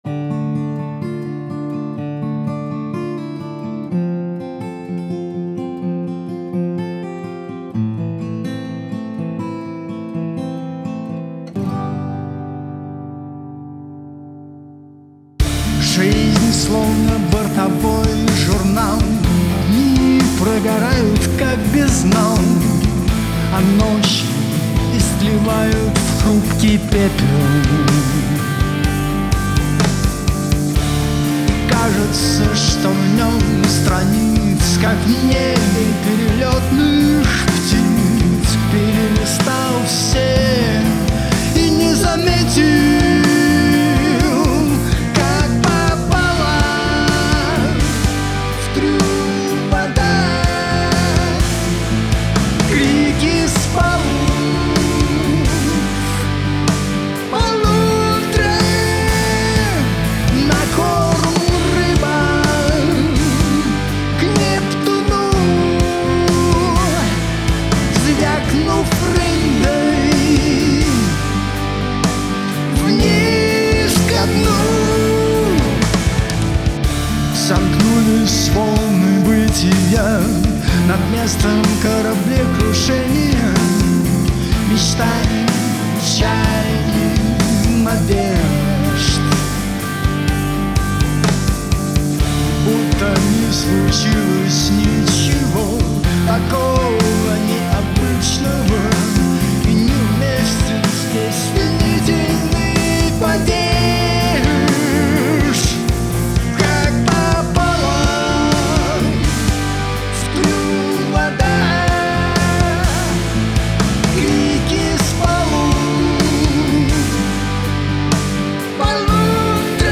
все инструменты, вокал, аранжировки, запись, сведение